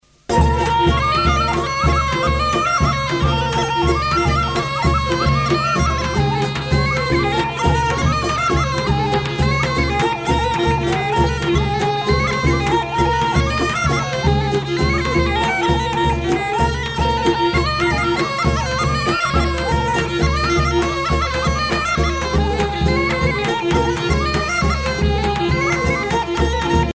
danse : ruchenitza (Bulgarie)
Pièce musicale éditée